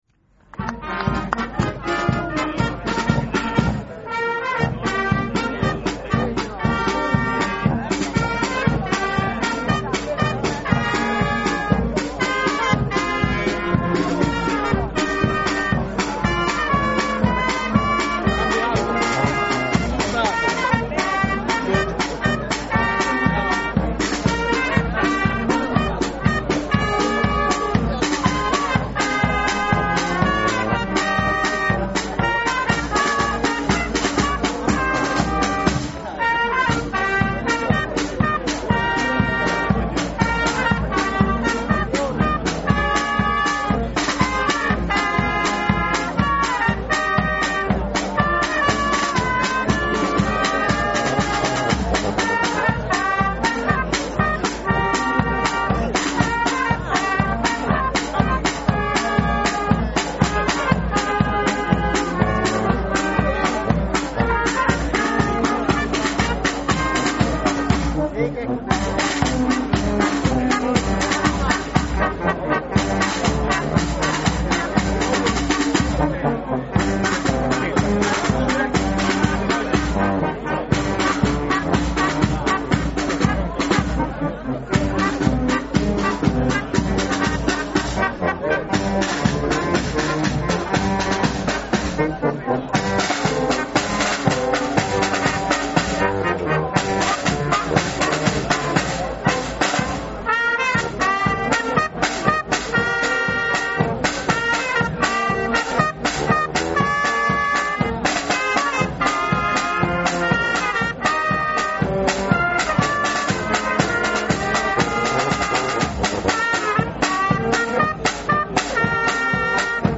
Rualis di Cividale del Friuli (UD), 3 Novembre 2024
CANTO DEL CORO E BRANO BANDISTICO